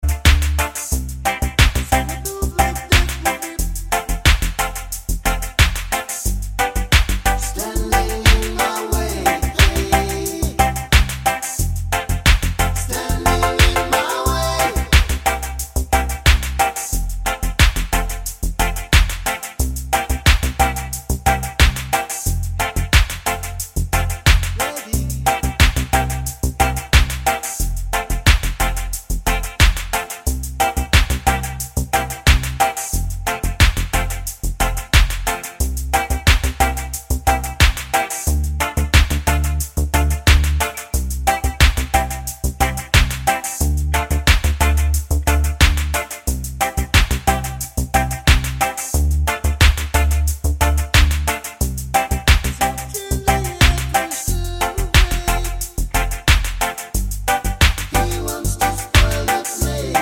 no Backing Vocals Reggae 4:13 Buy £1.50